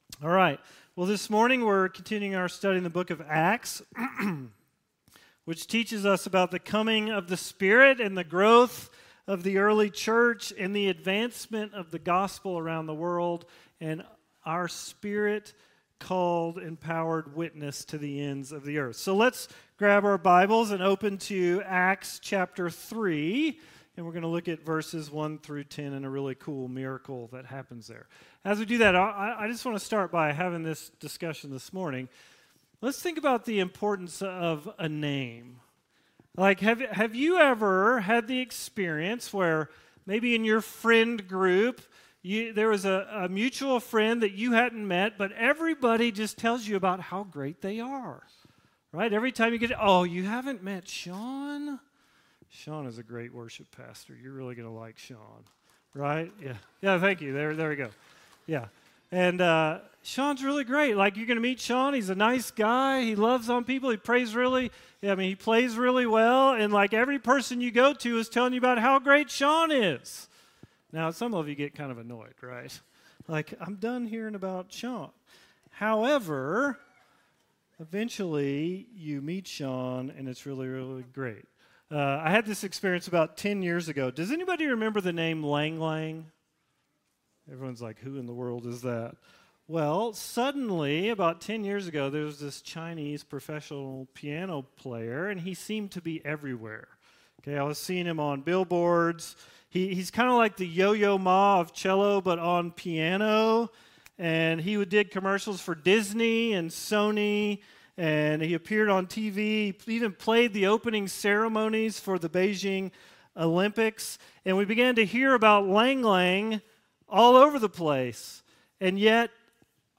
All Sermons - Risen Life Church